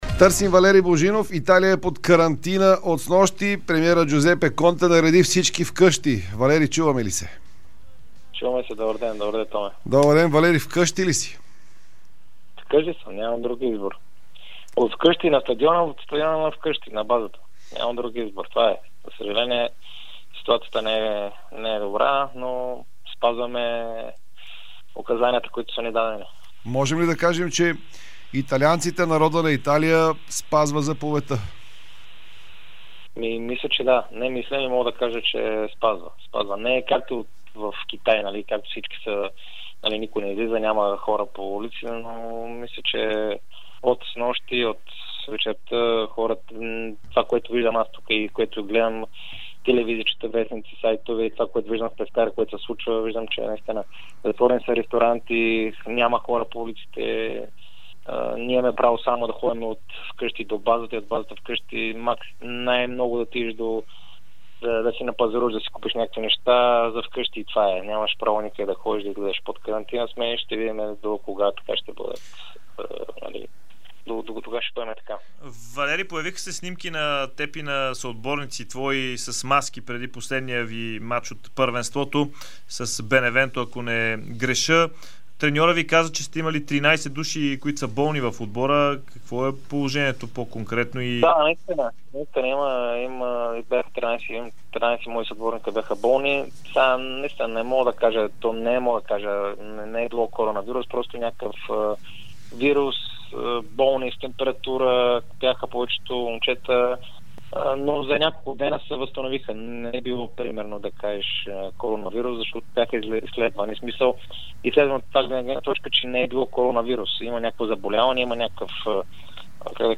Валери Божинов се включи в Спортното шоу на Дарик радио.